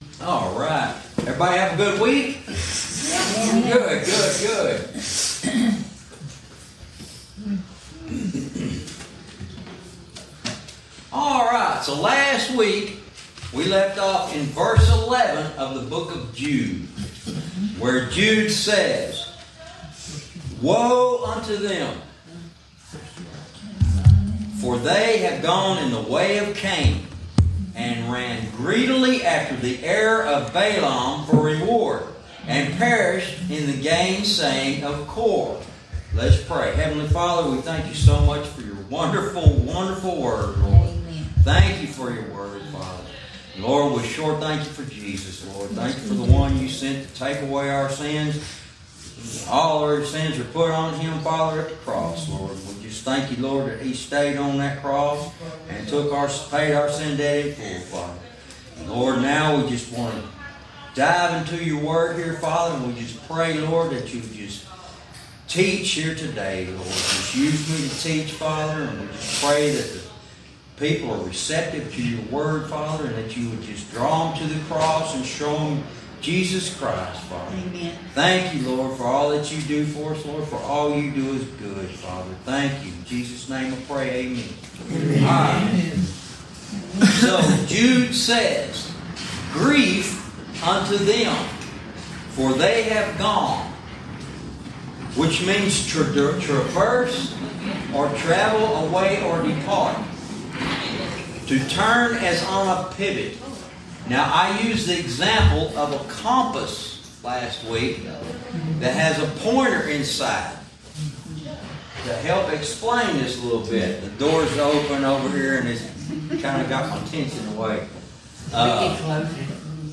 Verse by verse teaching - Lesson 39